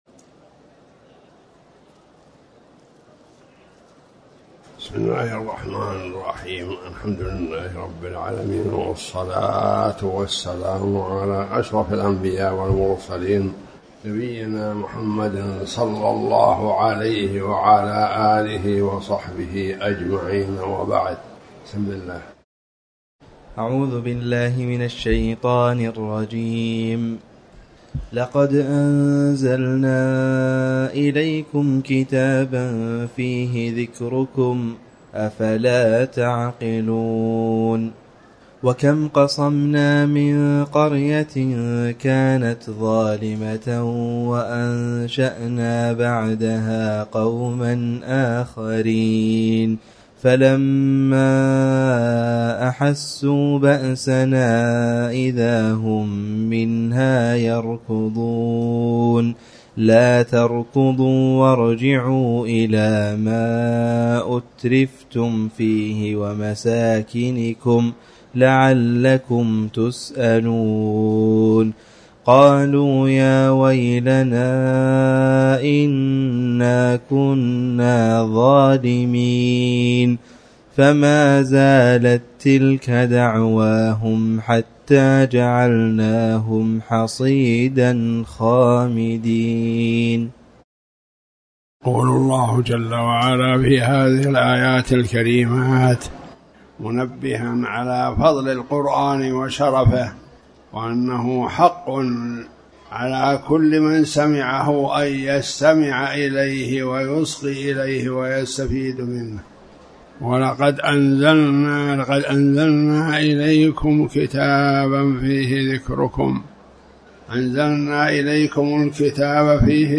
تاريخ النشر ١٧ جمادى الأولى ١٤٤٠ هـ المكان: المسجد الحرام الشيخ